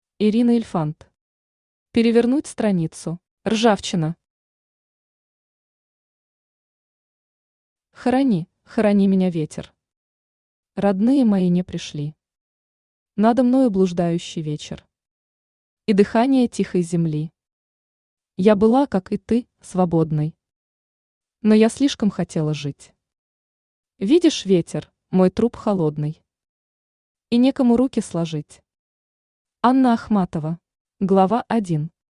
Аудиокнига Перевернуть страницу | Библиотека аудиокниг
Aудиокнига Перевернуть страницу Автор Ирина Ильфант Читает аудиокнигу Авточтец ЛитРес.